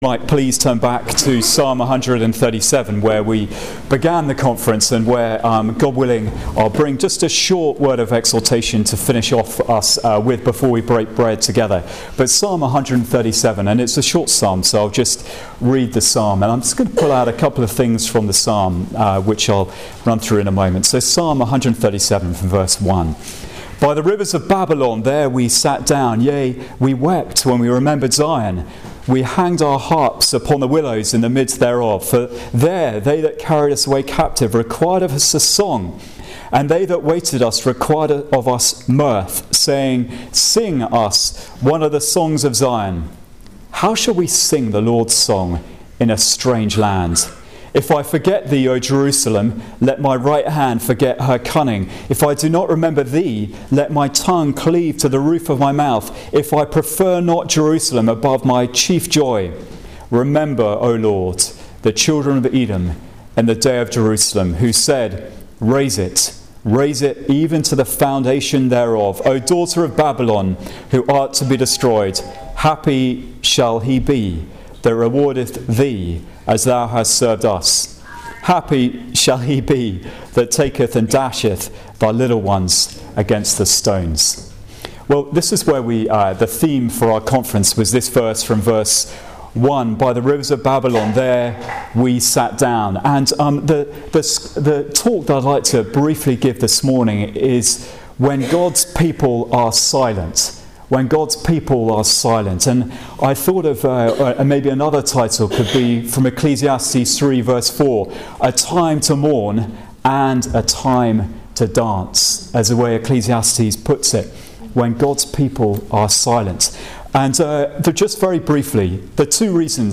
Psalm 137:1-2 Passage: Psalm 137 Service Type: Christian Alliance Ministries Conference « “They Overcame him” Submitting to the Lord Jesus »